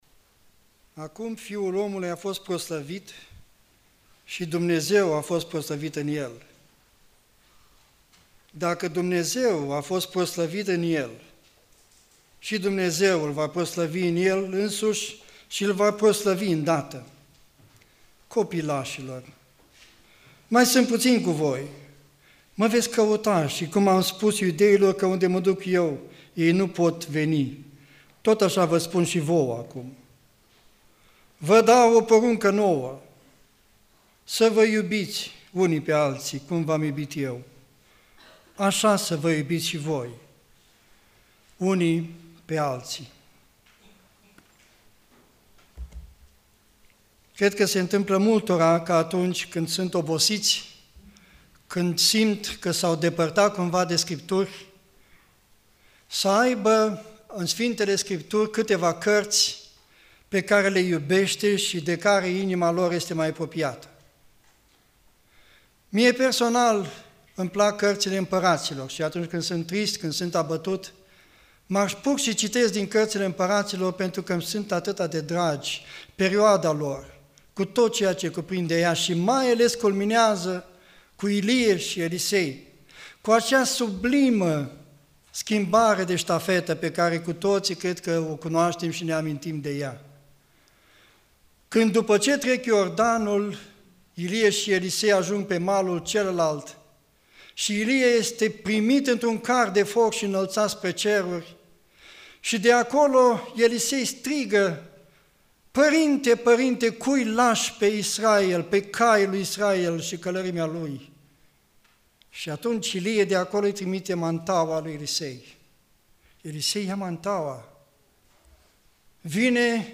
Predica Aplicatie 1 Timotei 1:1-11